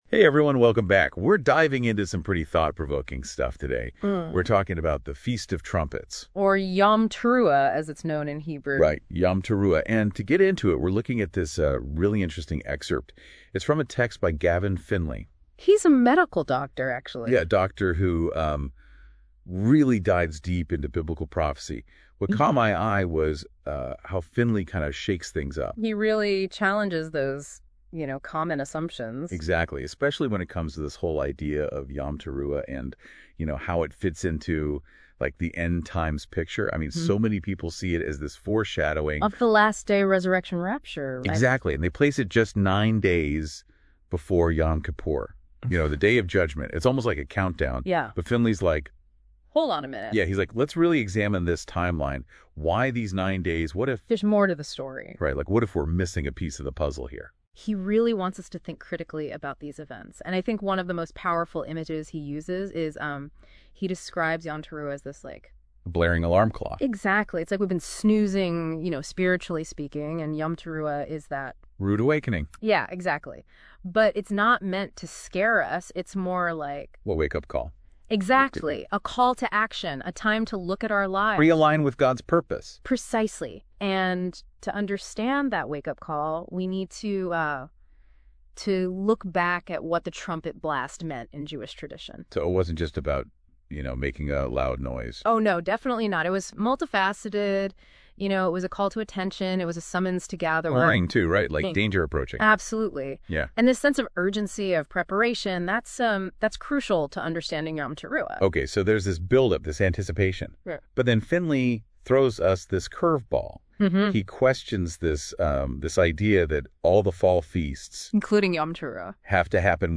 An mp3 Audio Commentary
of this article by Notebook LM